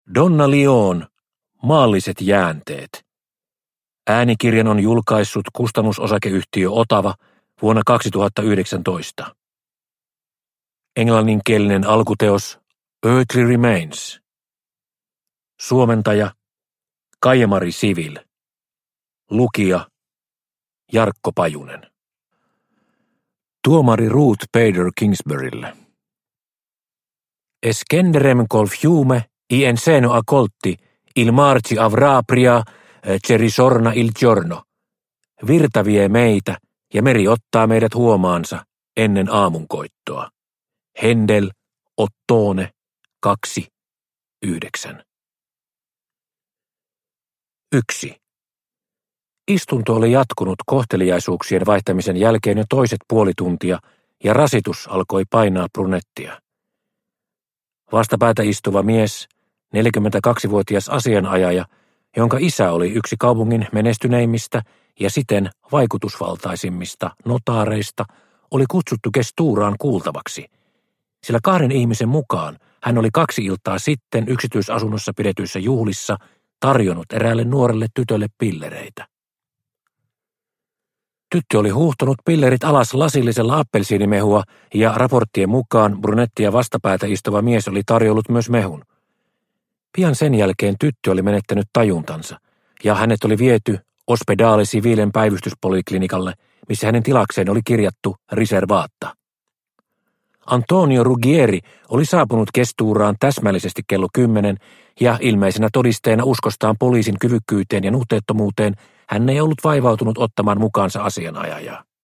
Maalliset jäänteet – Ljudbok – Laddas ner